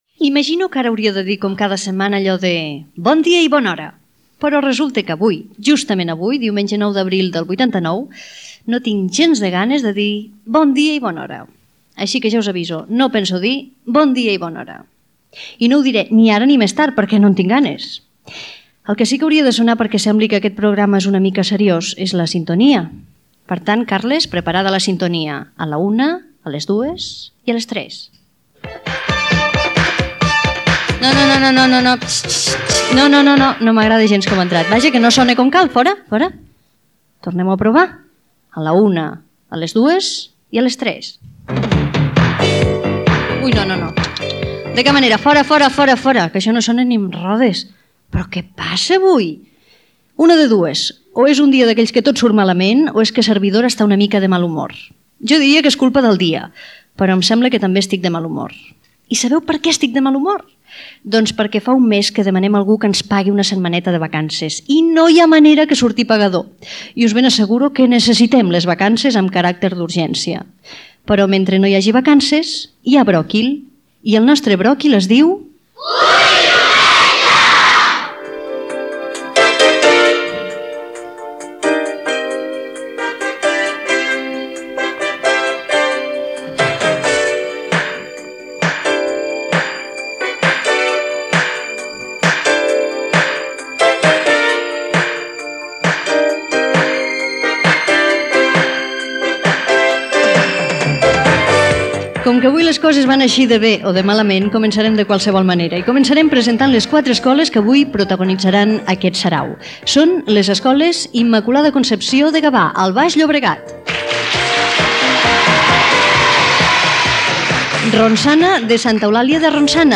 Bon dia i bon ahora, sintonia, ganes que alguna persona pagui les vacances, indicatiu del programa, presentació de les quatre escoles que participaran al programa, festivitats de Gavà